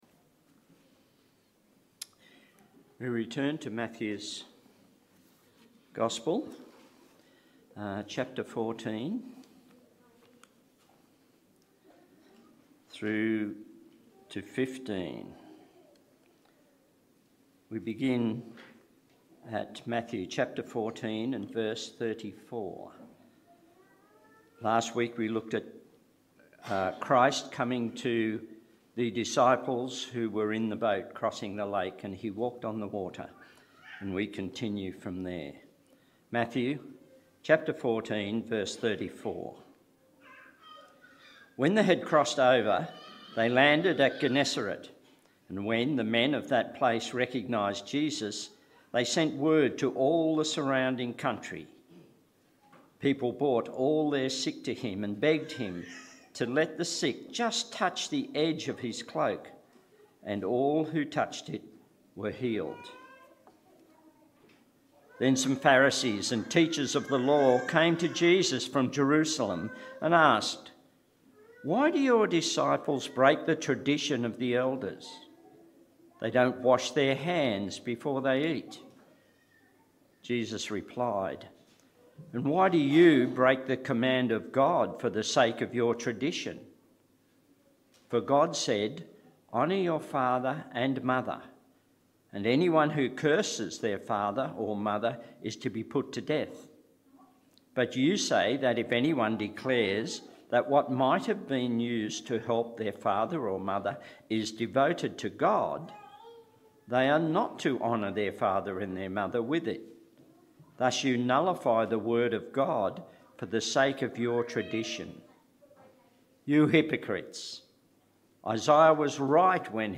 Sermons | Dickson Baptist Church